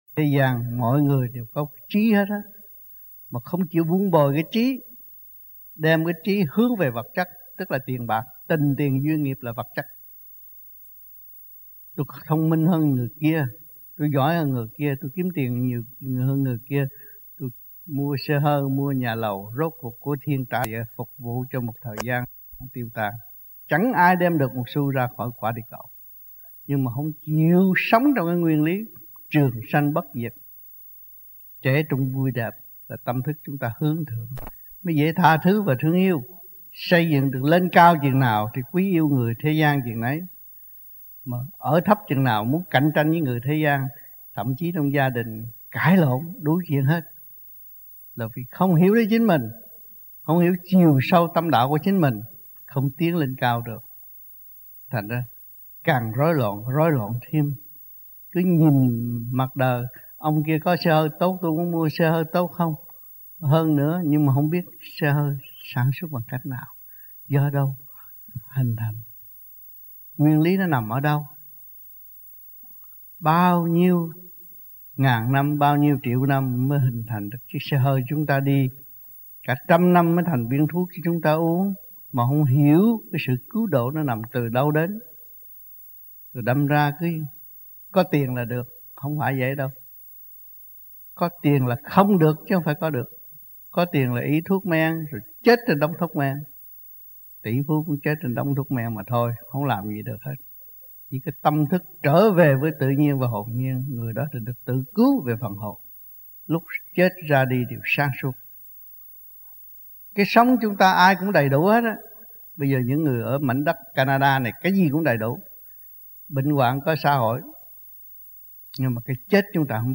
1995 Đàm Đạo
1995-04-16 - Montreal - Luận Đạo